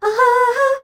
AHAAA   A.wav